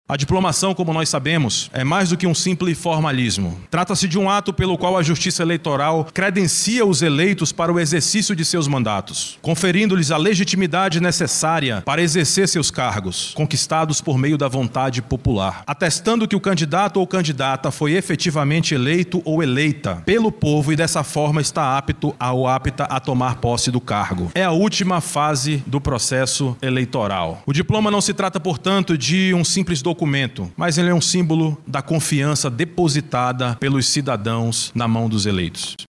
A cerimônia ocorreu no auditório do Tribunal de Justiça do Amazonas – TJAM, localizado na zona Centro-Sul da capital amazonense.
A diplomação é o ato que reconhece oficialmente a eleição dos candidatos e é a última etapa do processo eleitoral antes da posse, destaca o Rafael Rodrigo Raposo, juíz-presidente do Pleito Municipal de Manaus de 2024.